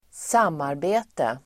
Uttal: [²s'am:arbe:te]